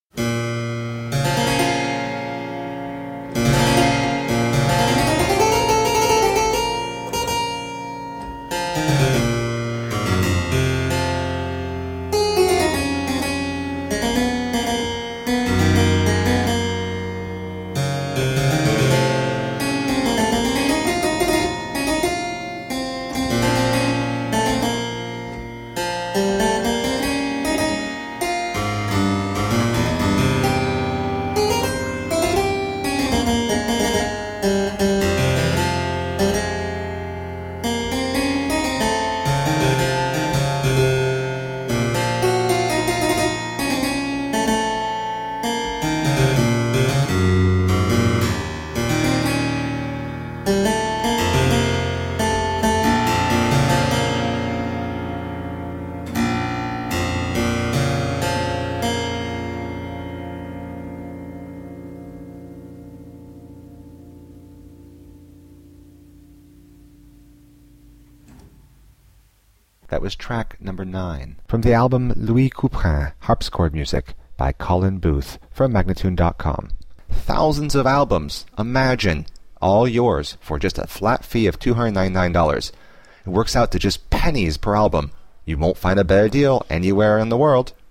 Solo harpsichord music.
played on a wonderful original French harpsichord of 1661